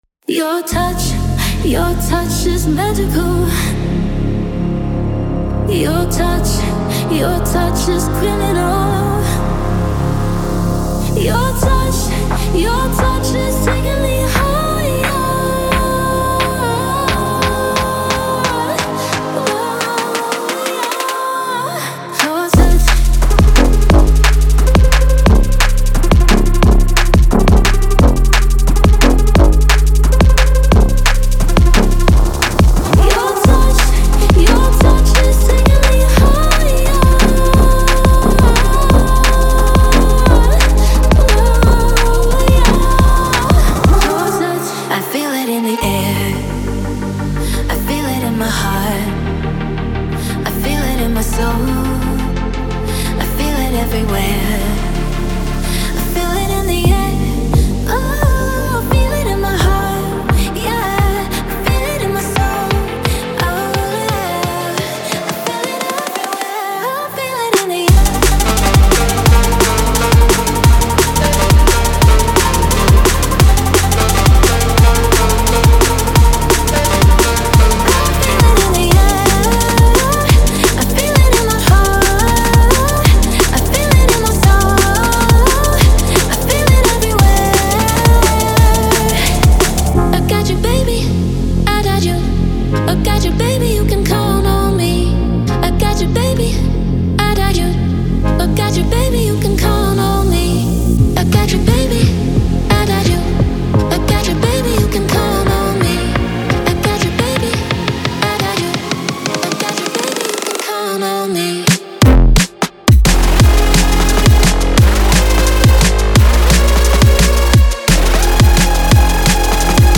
これらのボーカルはメロディアスで感情豊か、かつ非常に適応性が高く、クラブヒットやラジオ向けの作品の両方に理想的です。
なお、オーディオデモは大きく、圧縮され均一に聞こえるように処理されています。
Genre:Drum and Bass
174 – 176 BPM